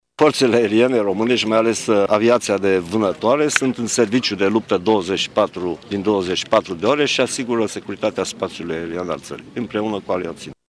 Duşa a precizat, însă, că aviaţia militară este în alertă 24 de ore din 24: